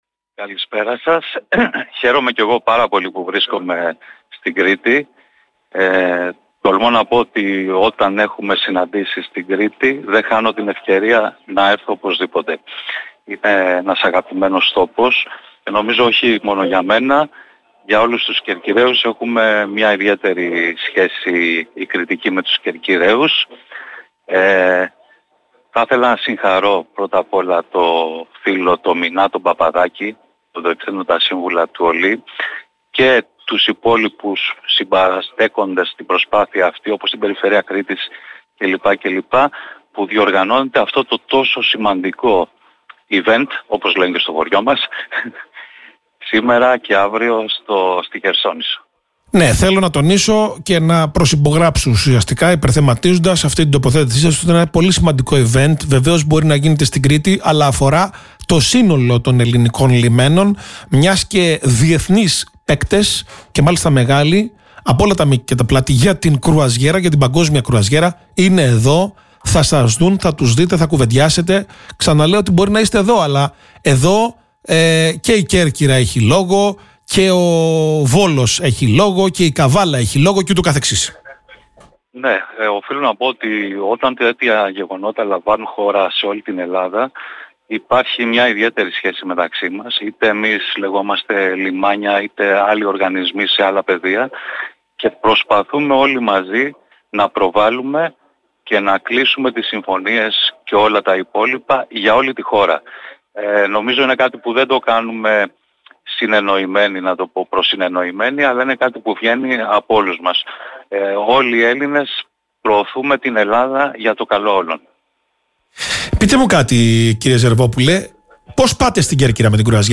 στο ραδιόφωνο του Politica 89.8